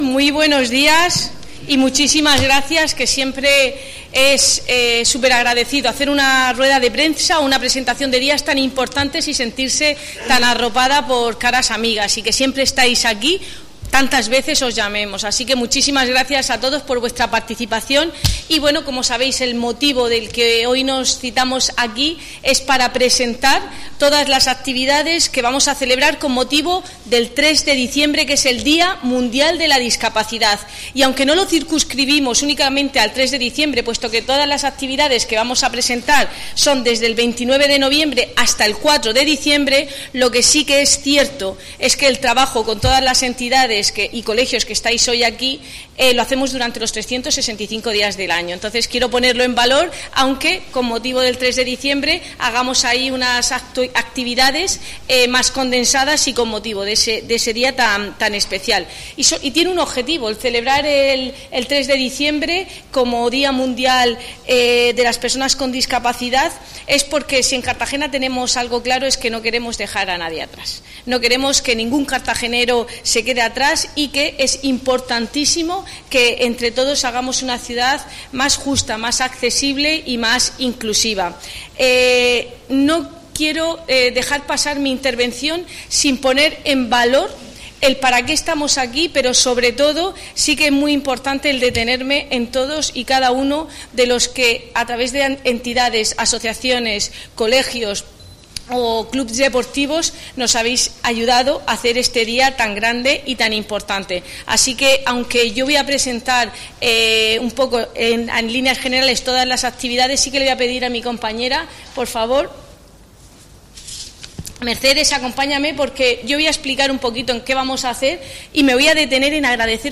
Audio: Presentaci�n del programa de actividades por el D�a Internacional de las Personas con Discapacidad (MP3 - 12,72 MB)
Cartagena celebra el Día Internacional de las Personas con Discapacidad el próximo 3 de diciembre con varios días de actividades inclusivas. La edil de Política Social, Igualdad y Familia, Cristina Mora, ha presentado esta amplia oferta lúdica que se desarrollará en diferentes puntos del municipio del 29 de noviembre al 4 de diciembre.